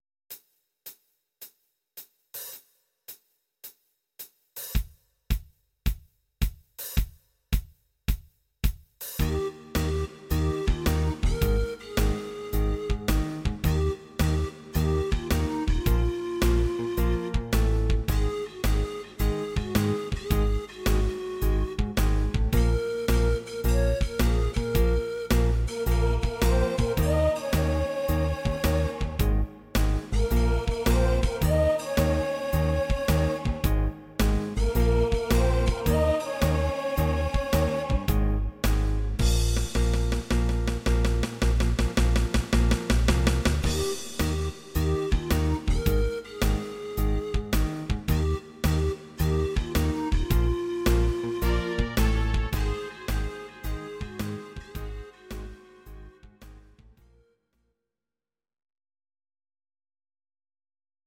Audio Recordings based on Midi-files
cover